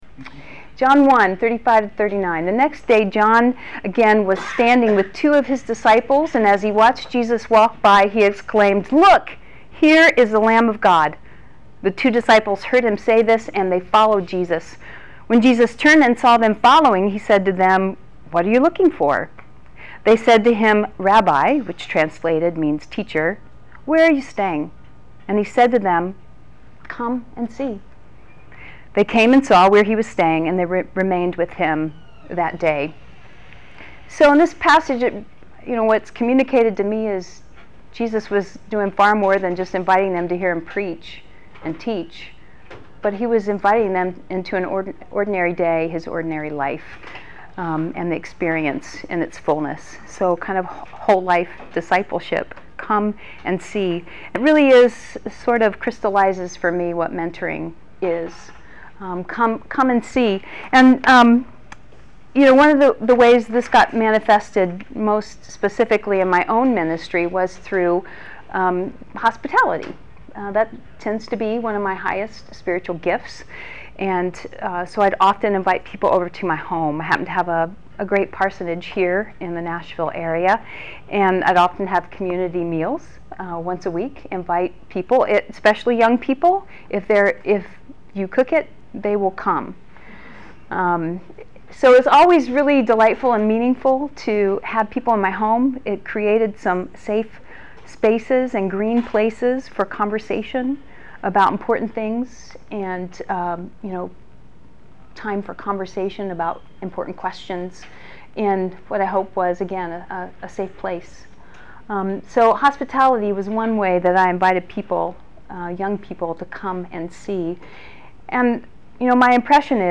Younger pastors crave the wise and careful attentiveness of more seasoned ministers in their lives. This workshop presents basic guidelines for finding or becoming a mentor, suggested topics/resources for your meetings, and a discussion about the variety of ways mentoring relationships can happen.